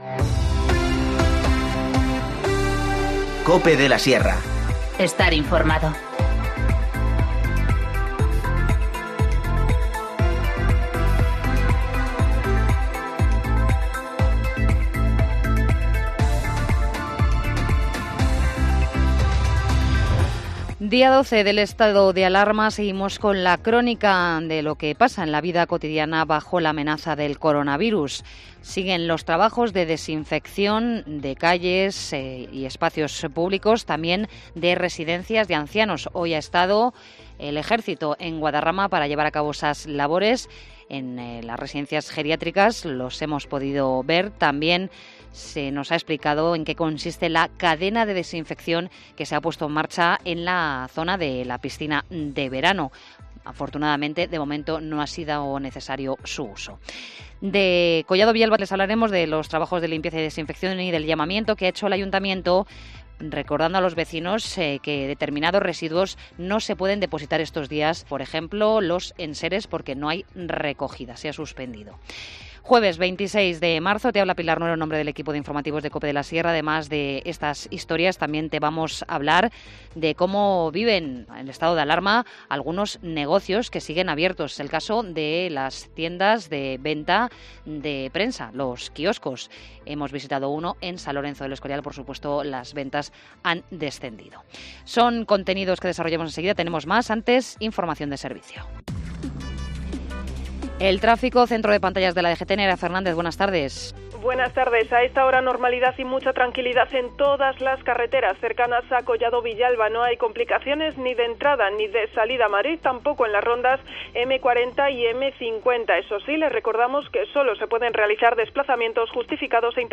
Informativo Mediodía 26 marzo 14:20h